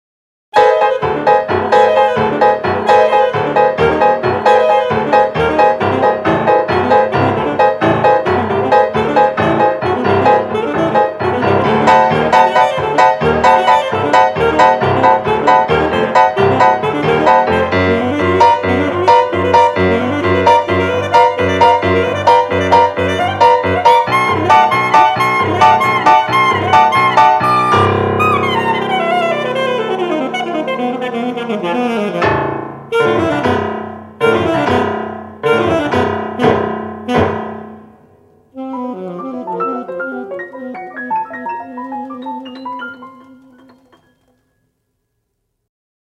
alto saxophone & piano